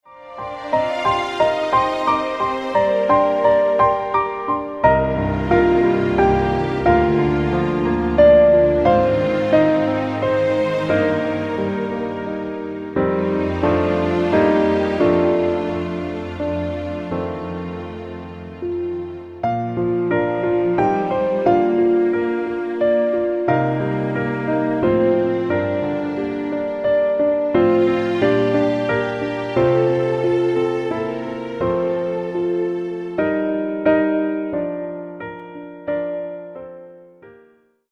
Piano - Strings - Low